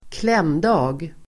Ladda ner uttalet
kläm|dag substantiv, working day [between two public holidays] Uttal: [²kl'em:da:g] Böjningar: klämdagen, klämdagar Definition: arbetsdag mellan två helgdagar som man ofta arbetar in (often worked in in advance)